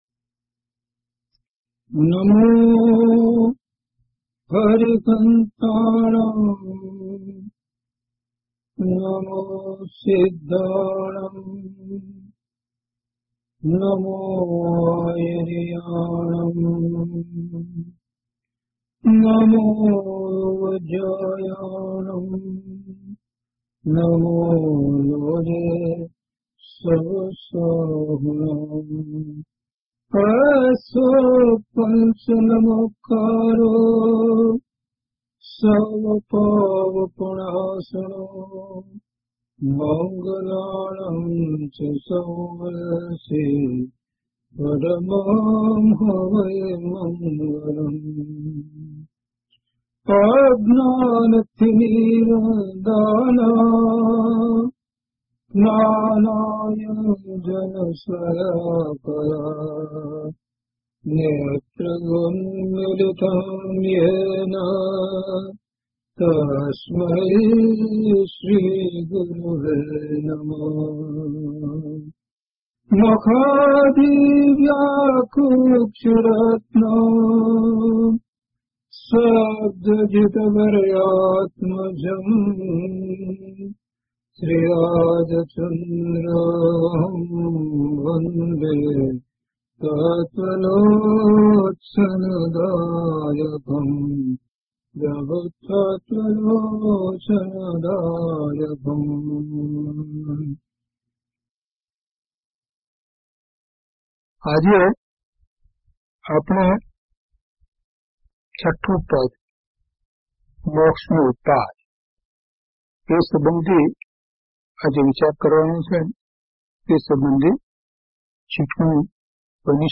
DHP079 Samyag Darshan (Chha Pad) part-4 - Pravachan.mp3